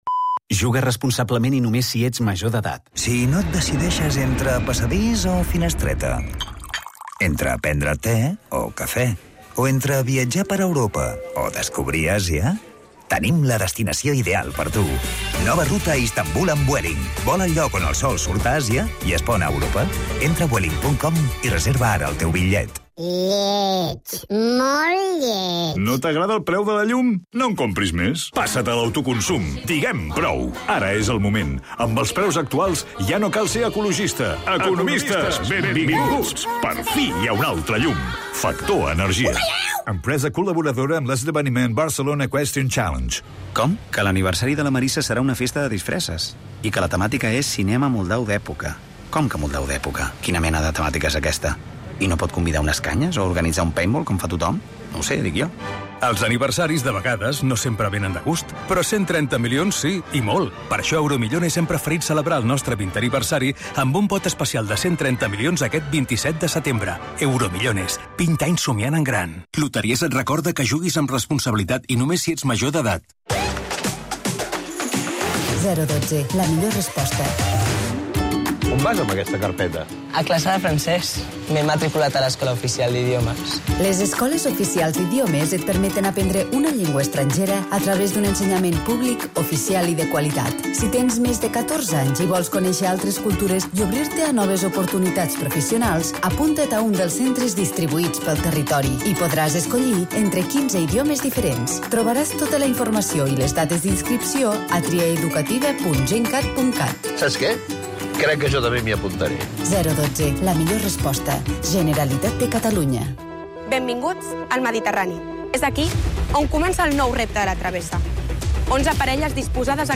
El mat, de 9 a 10 h (tertlia) - 23/09/2024
Entrevistem Oriol Junqueras, expresident d'ERC i candidat a la reelecci. Analitzem com funciona la seguretat durant les festes de la Merc i com van els controls especfics per interceptar armes blanques.